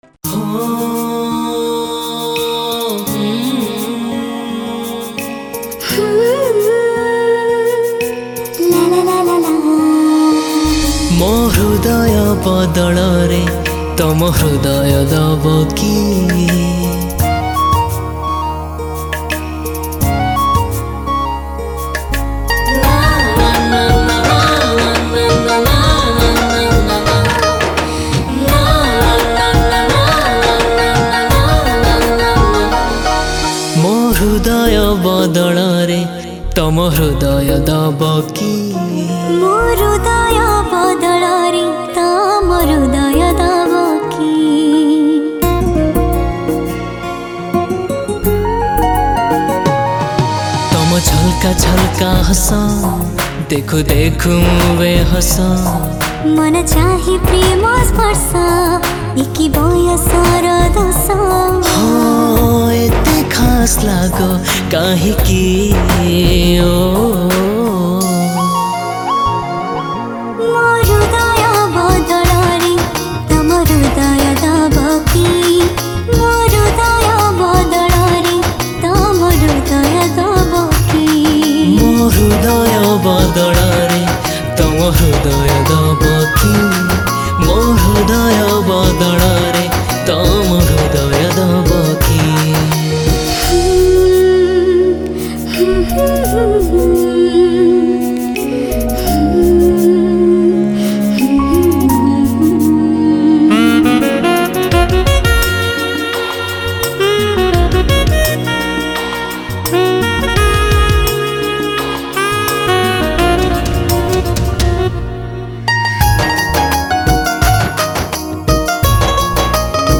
Single Odia Album Song 2022